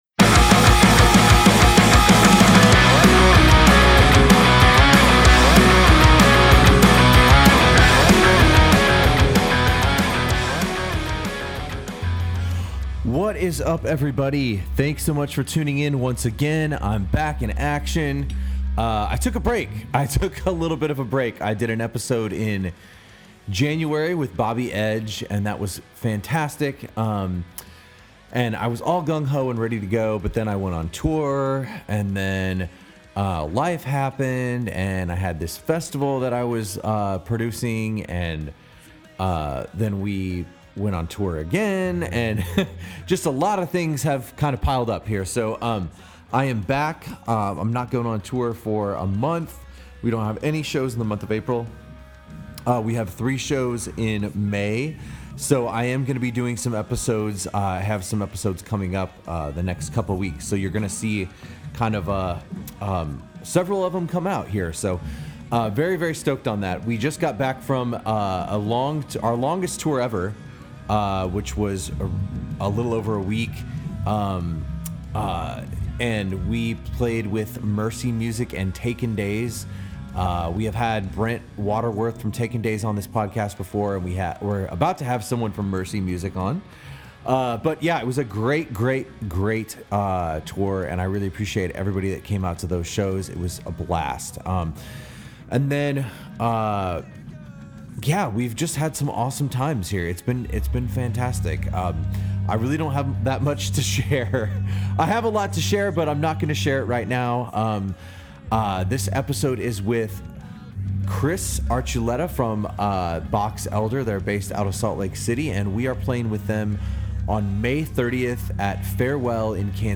Be a guest on this podcast Language: en Genres: Music , Music Interviews Contact email: Get it Feed URL: Get it iTunes ID: Get it Get all podcast data Listen Now...